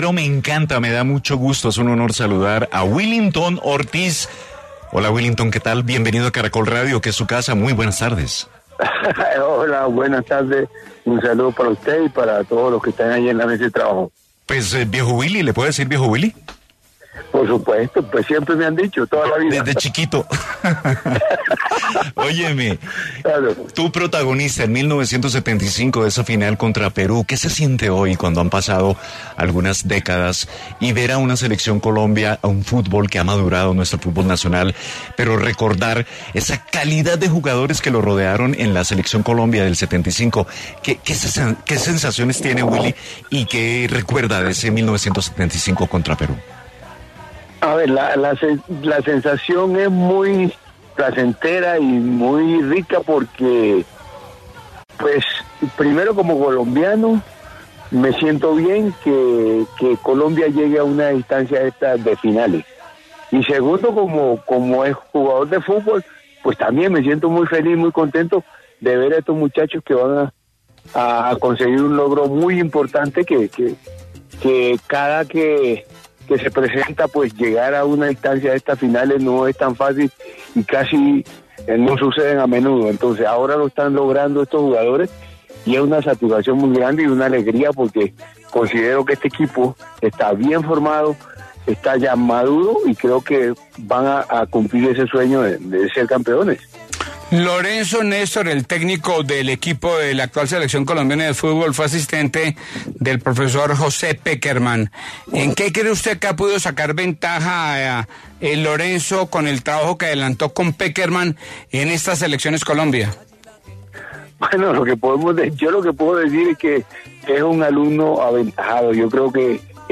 Willington Ortiz, histórico atacante colombiano, de 72 años, quien es considerado por muchos como uno de los mejores jugadores de la historia de Colombia, habló en el noticiero deportivo del mediodía sobre el momento que vive la Selección Colombia, analizó la gran final de la Copa América y aseguró que ve cerca la consecución de la segunda Copa para el país.